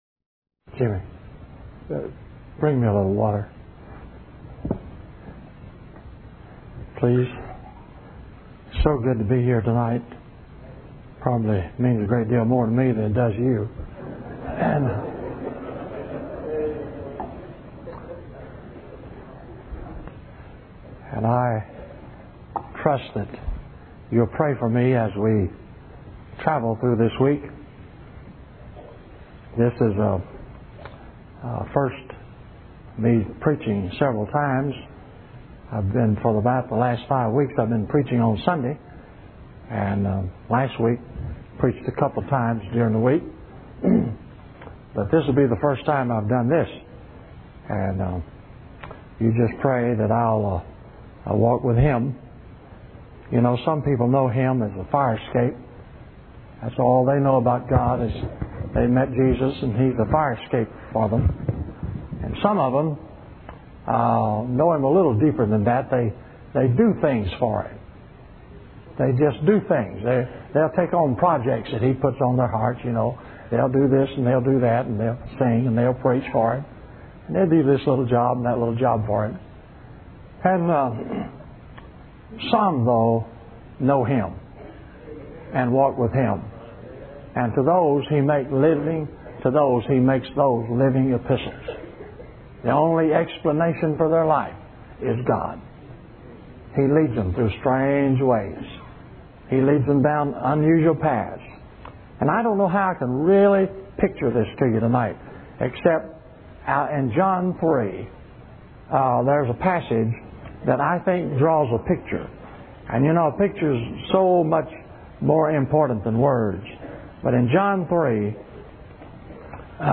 In this sermon, the preacher emphasizes the importance of actively seeking and accepting God's plan for our lives. He uses the story of Gideon and the 300 men to illustrate how God can use ordinary vessels to reveal His glory.